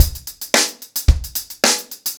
TupidCow-110BPM.3.wav